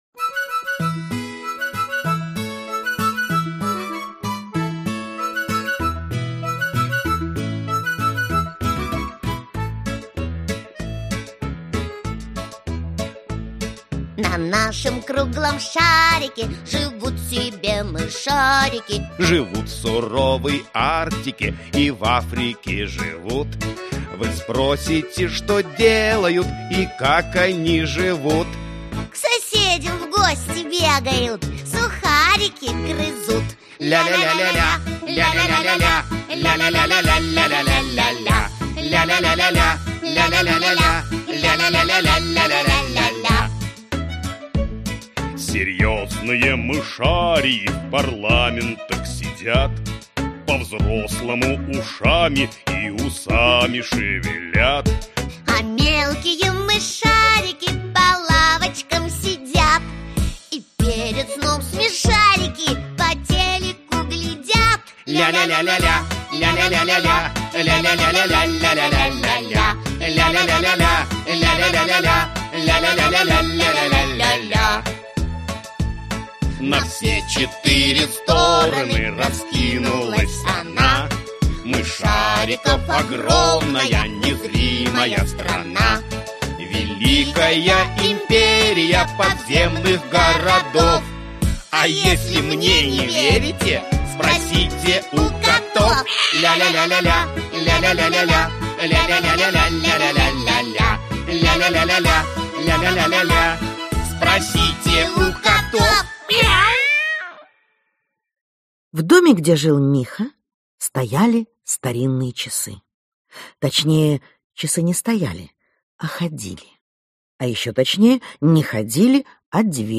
Аудиокнига Путешествие в Мышляндию | Библиотека аудиокниг
Aудиокнига Путешествие в Мышляндию Автор Андрей Усачев Читает аудиокнигу Ирина Муравьева.